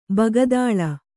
♪ bagadāḷa